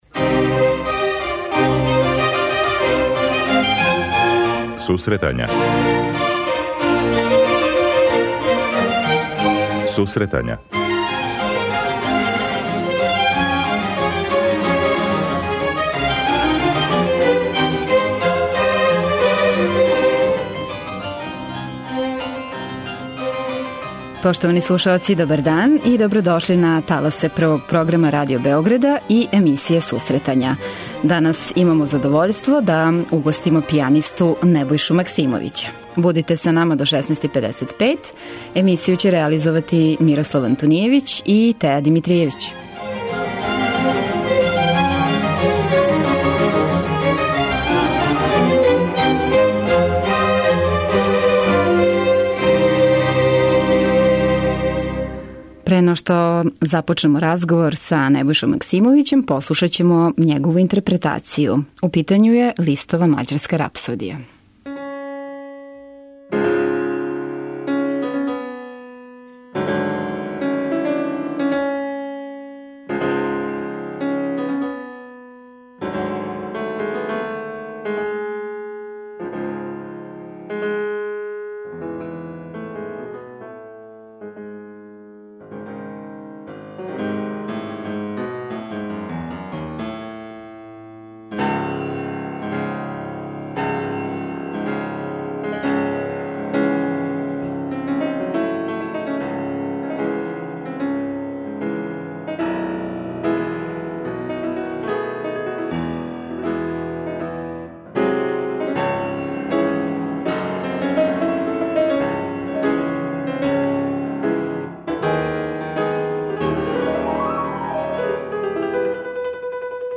Најављујемо концерт у Галерији АРТГЕТ и слушамо композиције Шопена и Листа.